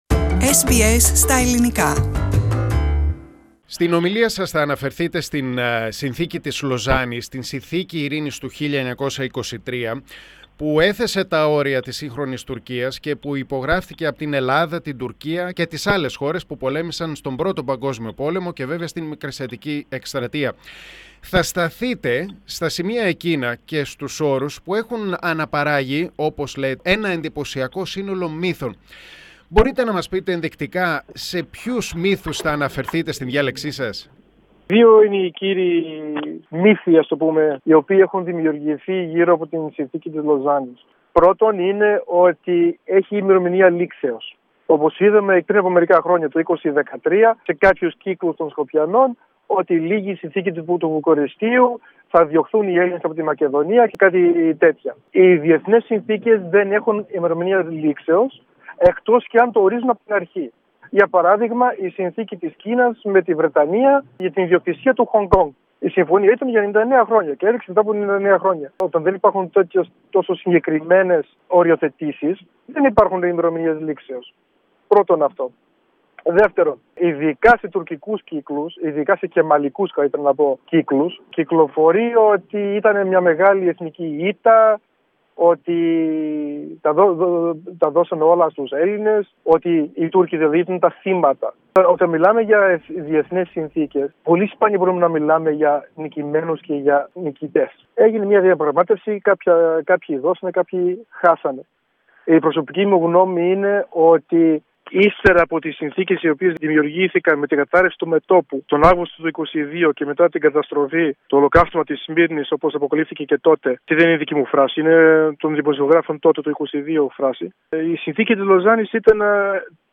SBS Ελληνικά